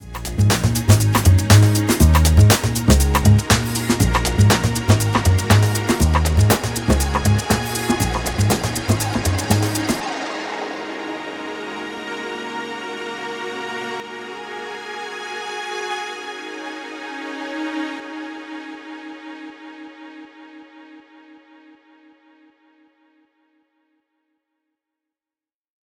The bass is then faded out in volume and the main drum loop is filtered over the same time using a very simple stock low pass filter.
The Automation based build.
This is all simple stuff but combined it creates a slow mutation and a wash of reverb that moves us into the final part of the arrangement very smoothly.